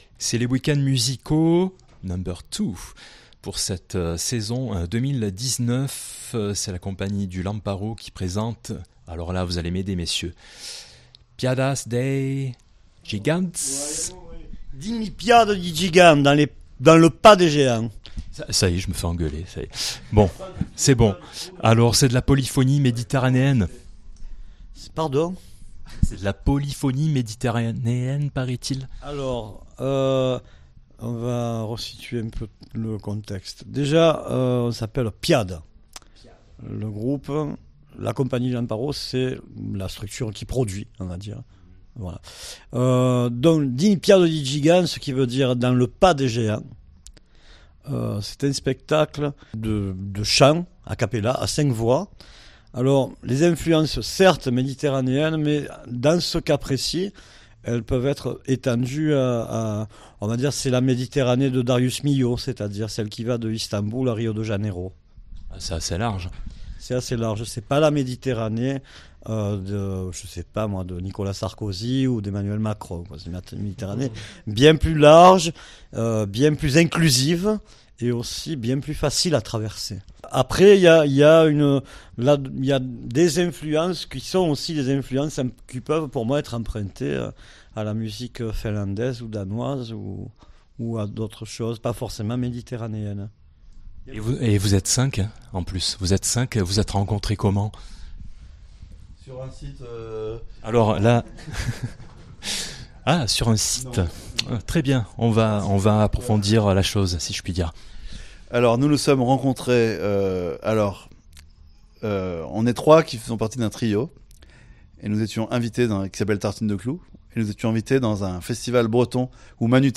Ils étaient nos invités, le groupe Polyphonique
Ils seront en concert dès ce jeudi 21 février jusqu'au dimanche 24, dans le cadre des week-ends musicaux, chapitre 2 de Chaillol Profitez de ce moment de radio avec cette émission spéciale...